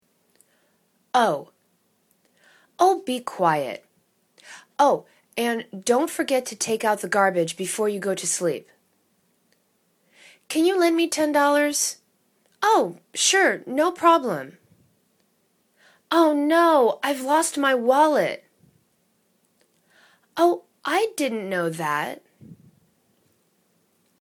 oh /o:/ interjection